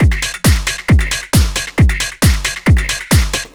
Session 04 - Mixed Beat 02.wav